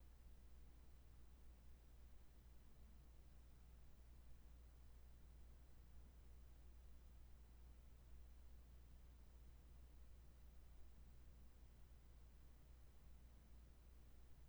And, finally, the mic swaddled in a blanket, plugged in to the laptop, which is plugged in to the mains, with the neighbor’s ac running.